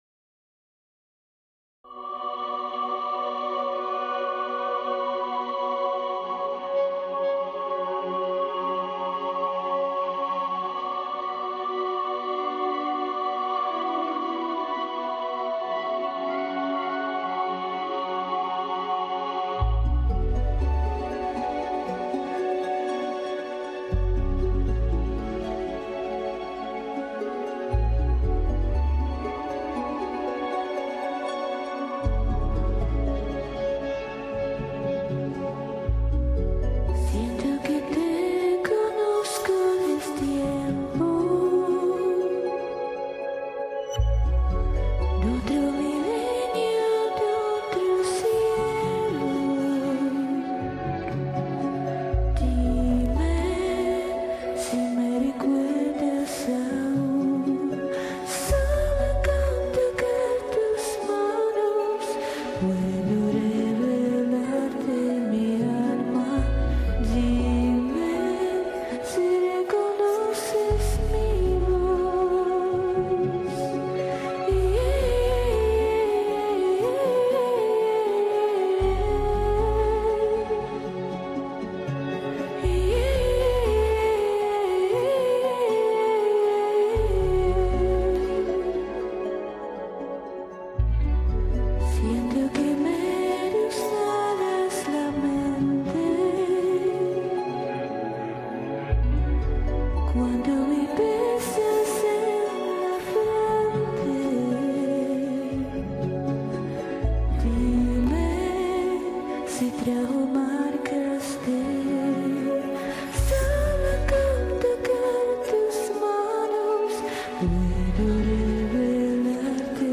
Комментарий инициатора: Очень красивая и мелодичная песня!